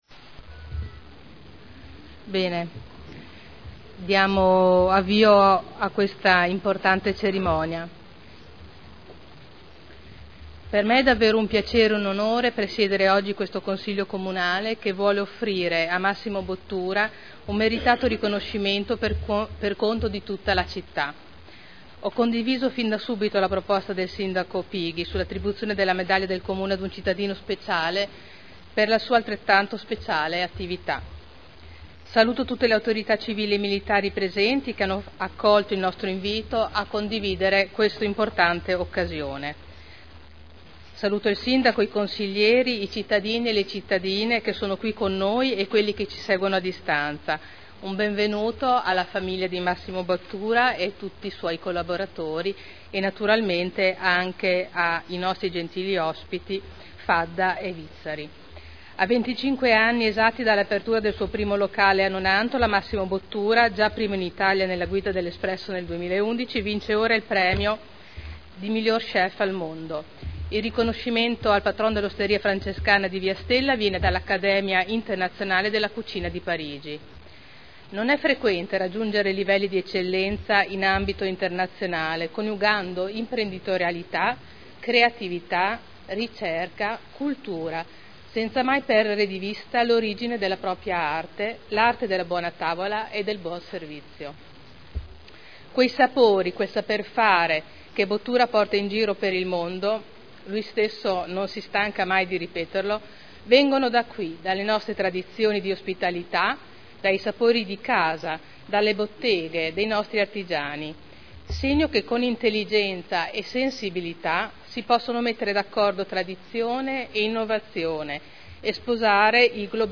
Seduta del 04/04/2011. Apre la Cerimonia di premiazione a Massimo Bottura. In Consiglio comunale il sindaco Pighi ha consegnato una medaglia al cuoco modenese per celebrare il riconoscimento di “migliore chef del mondo”